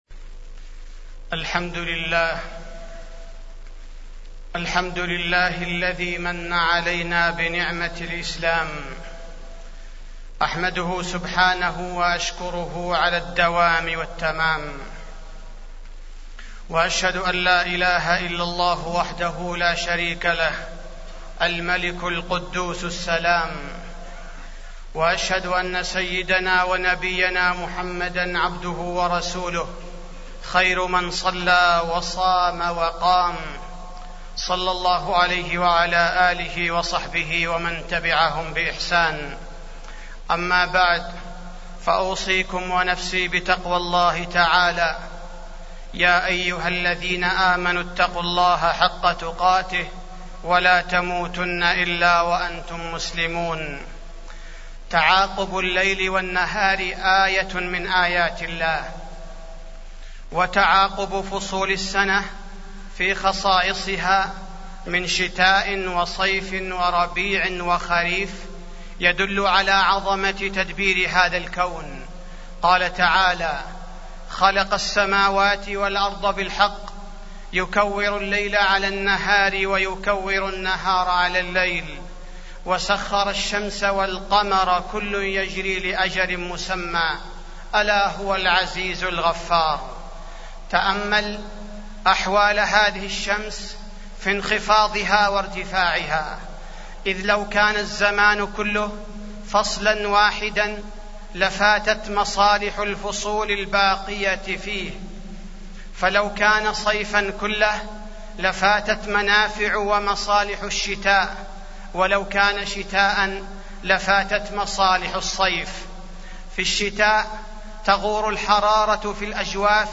تاريخ النشر ٢٣ محرم ١٤٢٩ هـ المكان: المسجد النبوي الشيخ: فضيلة الشيخ عبدالباري الثبيتي فضيلة الشيخ عبدالباري الثبيتي فلسطين والشتاء